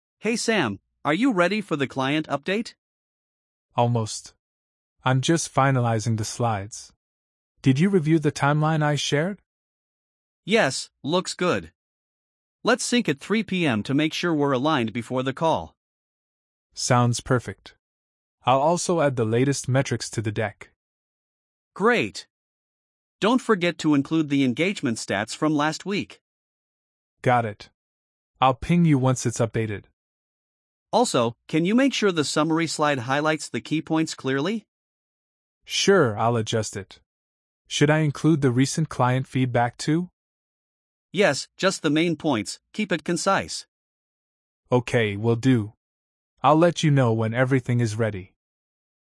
🤝 Two colleagues prepare together for an important client update.